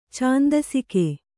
♪ cāndasike